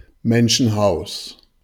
Audioaufzeichnung der Aussprache eines Begriffs. Sprache InfoField Deutsch Transkription InfoField Menschenhaus Datum 13.